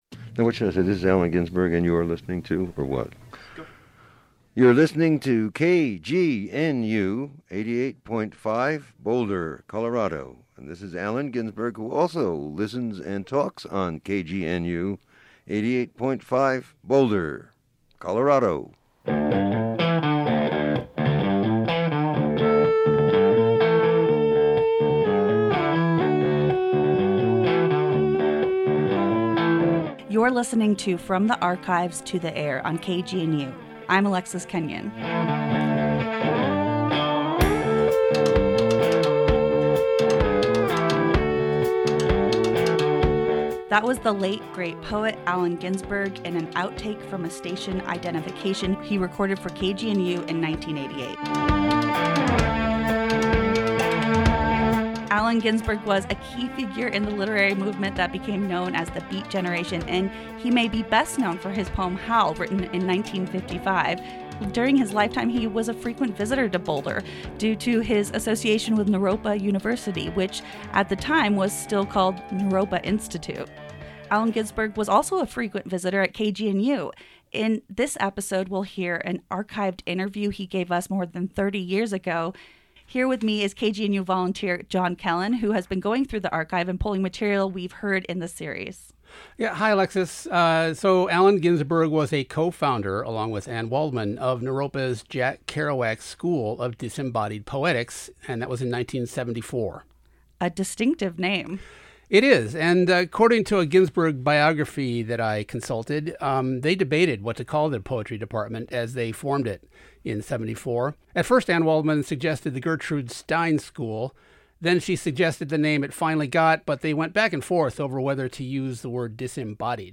Ginsberg discusses all of this in KGNU’s 1988 archival interview.